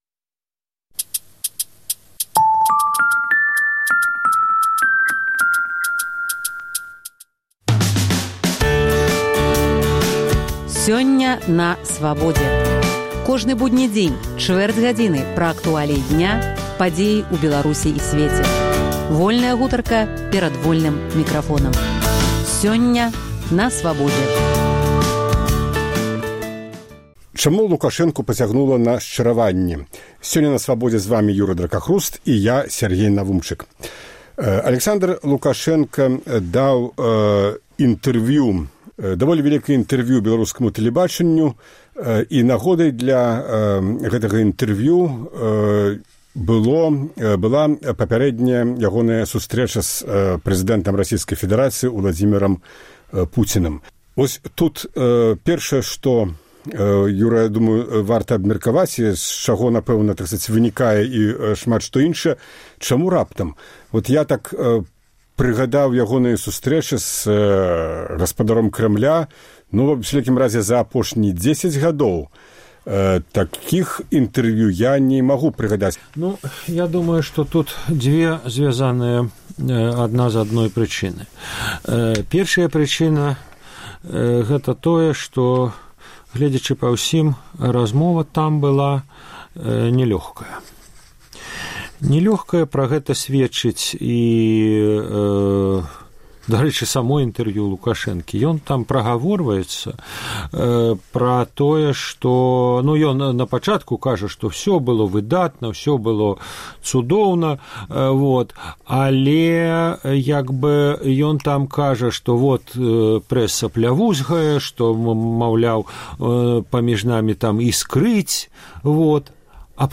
Абмяркоўваюць